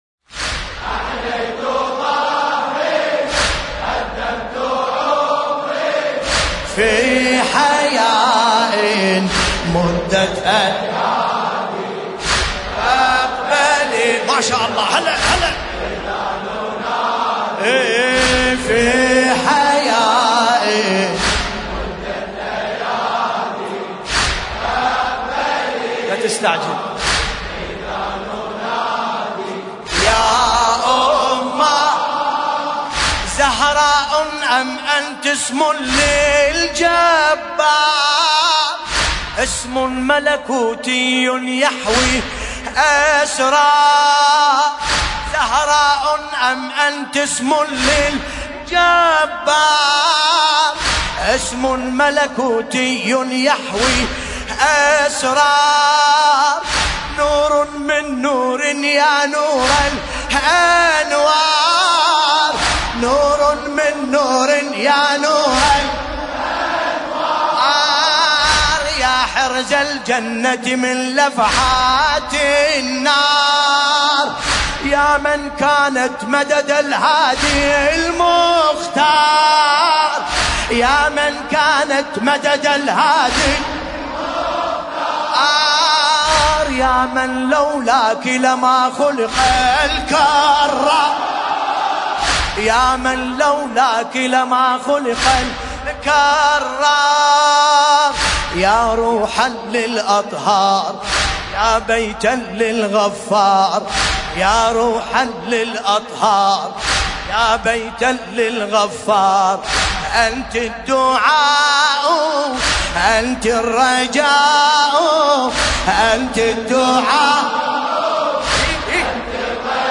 المناسبة : الليالي الفاطمية الثانية 1440